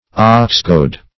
Meaning of oxgoad. oxgoad synonyms, pronunciation, spelling and more from Free Dictionary.
Oxgoad \Ox"goad`\